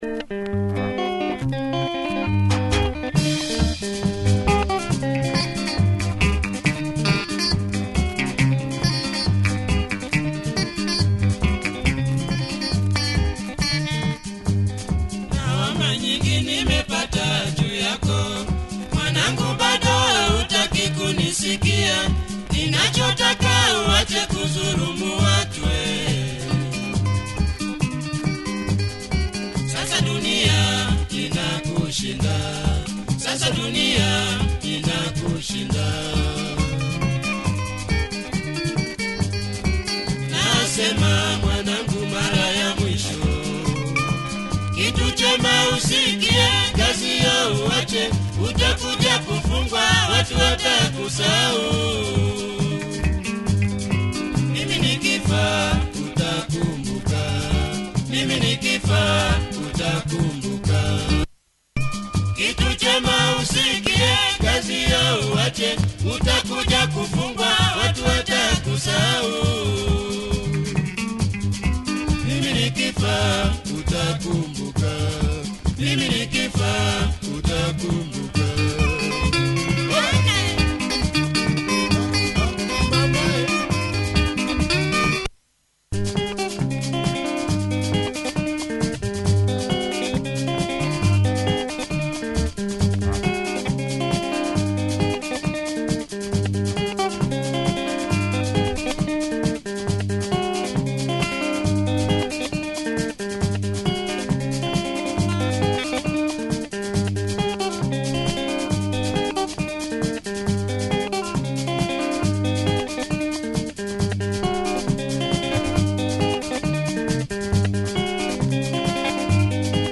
Always good tempo
and lovely guitar drive